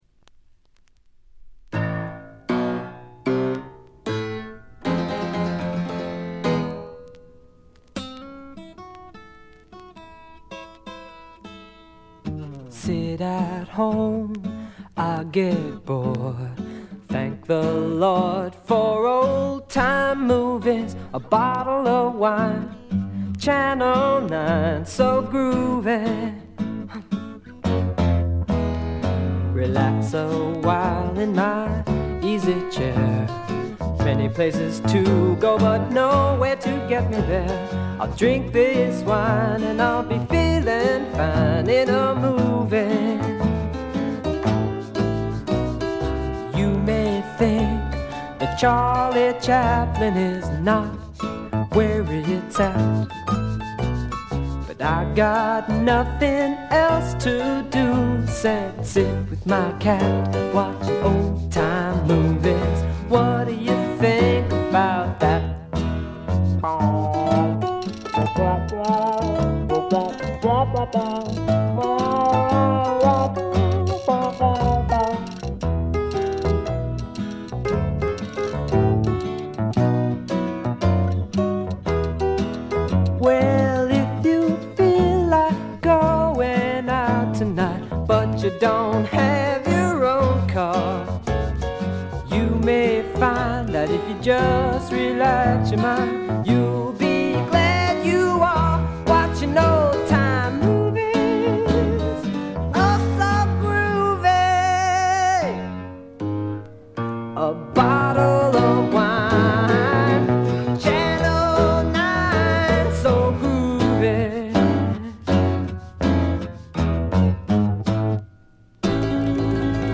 シンガーソングライター・デュオ
きらきらときらめくドリーミーフォーク的な感覚も素晴らしい。
試聴曲は現品からの取り込み音源です。